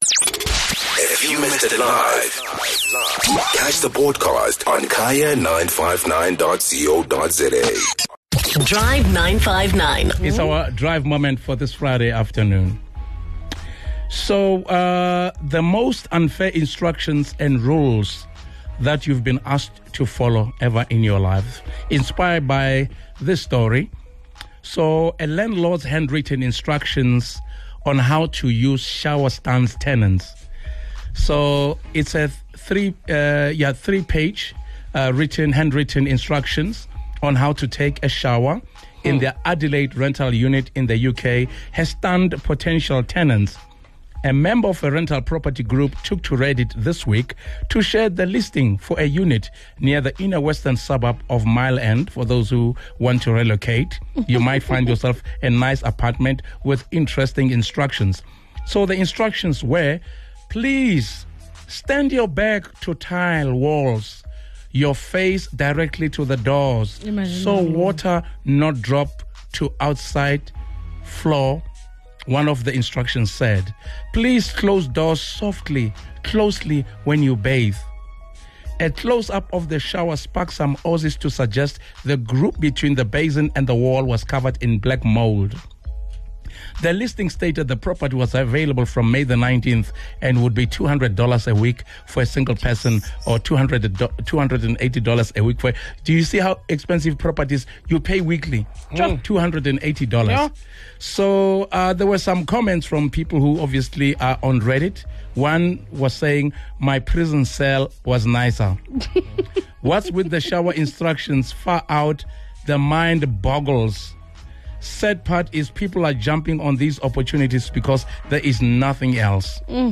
Have you ever encountered rules or instructions that didn't make any sense to you? The Drive 959 team heard some interesting rules that listeners struggled to follow.